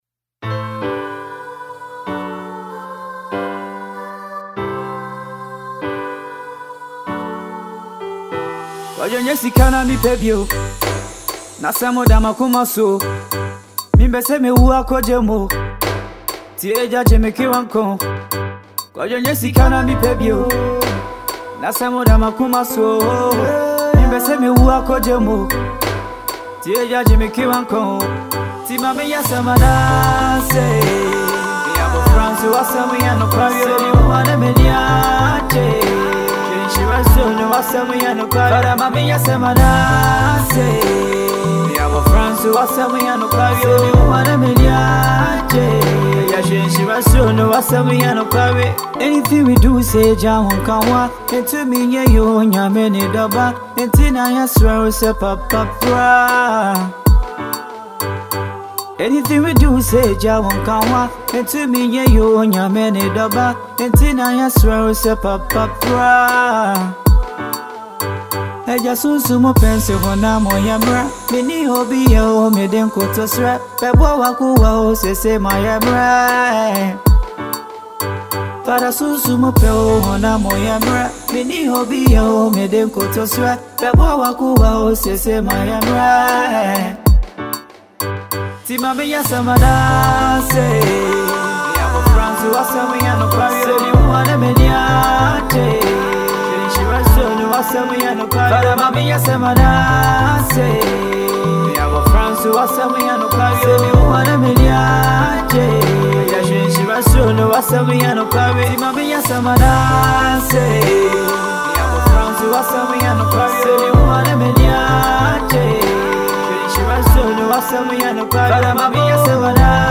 With sharp lyricism and heartfelt delivery